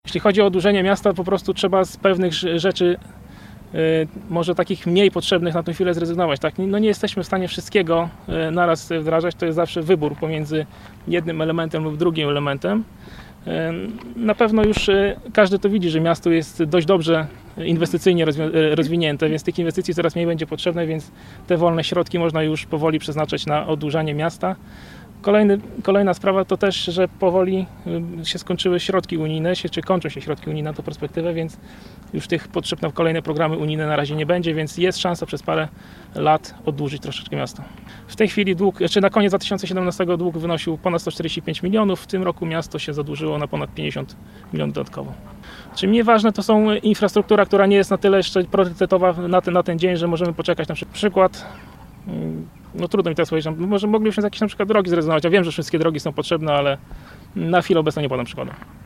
Swoje postulaty kandydat przedstawił na konferencji prasowej na wyspie na zalewie Arkadia wśród kandydatów Koalicji Obywatelskiej do Rady Miejskiej.